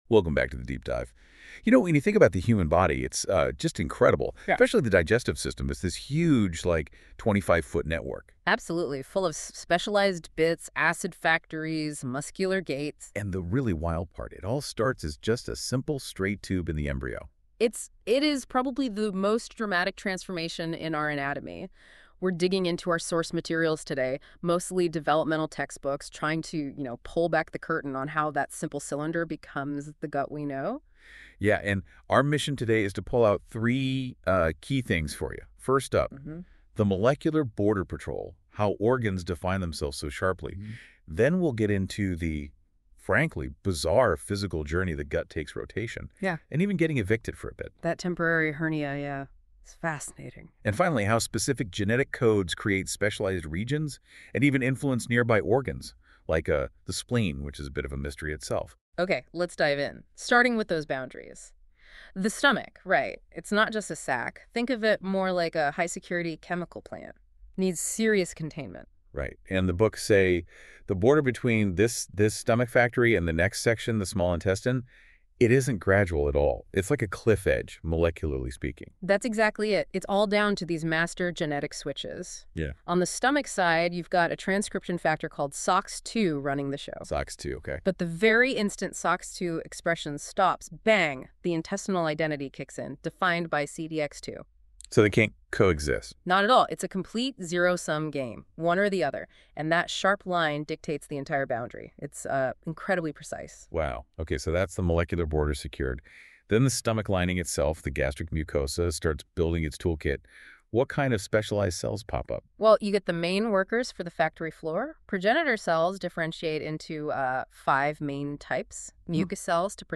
カールソンの発生学の教科書から、小腸の発生の章に関して、Google NotebookLMに音声概要を作ってもらいました。